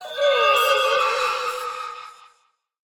Minecraft Version Minecraft Version 25w18a Latest Release | Latest Snapshot 25w18a / assets / minecraft / sounds / mob / allay / idle_without_item1.ogg Compare With Compare With Latest Release | Latest Snapshot